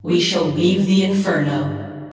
I am male, but I replaced them with AI voice tones to achieve female voice conversion.